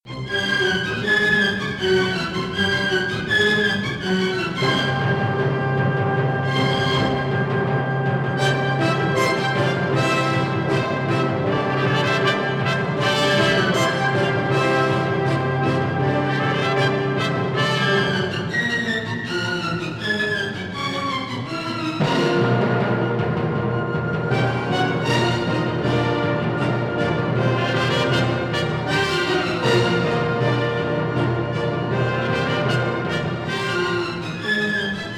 organ
Stereo recording made in Dvořák Hall, Prague 22- 24 May 1961